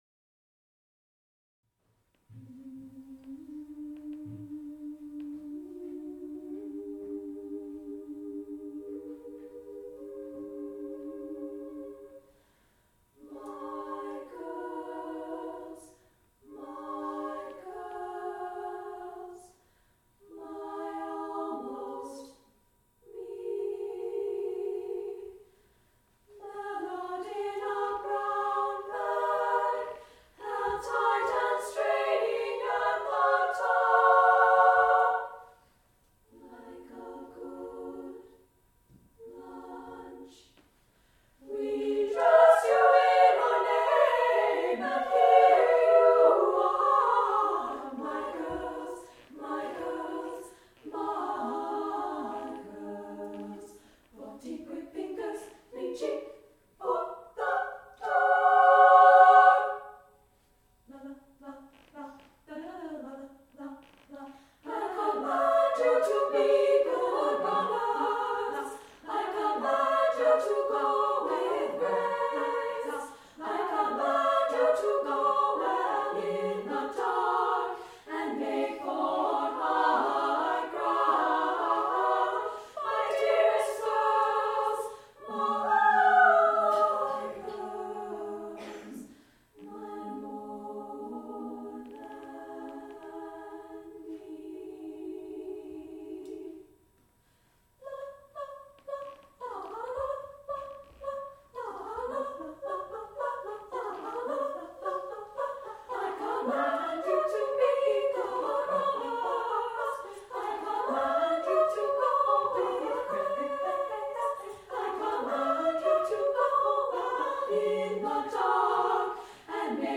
for SSA Chorus (1998)
There is energy and humor in both the poetic and musical language.